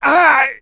Zafir-Vox_Damage_03.wav